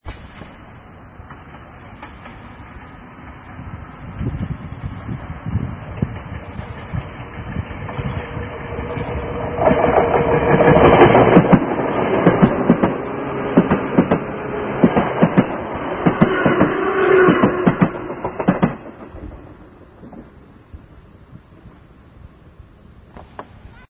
元テープは古く、また録音技術も悪いため、音質は悪いかも知れません。
C　６０１＋４１１　（１４０ＫＢ　２３秒）　７７年　鷺ノ宮西方にて通過音　ＴＲ１１のきしむ音が聴こえる